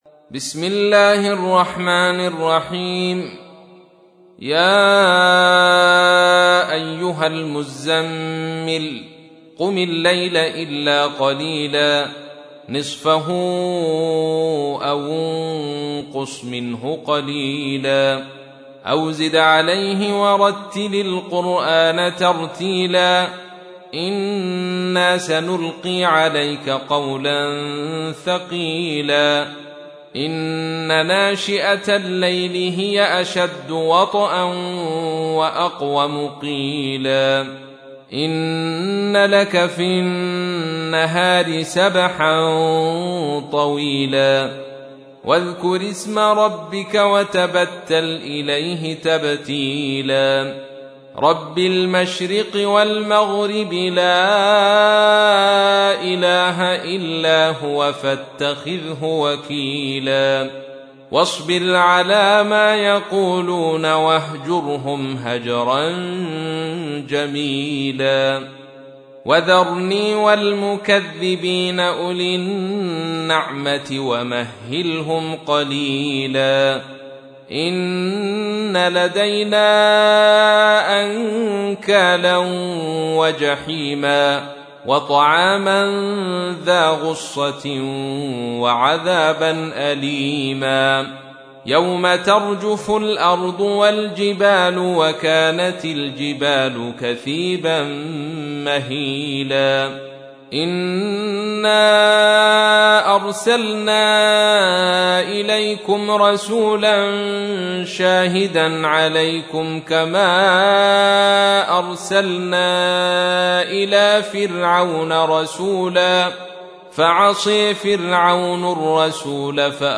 تحميل : 73. سورة المزمل / القارئ عبد الرشيد صوفي / القرآن الكريم / موقع يا حسين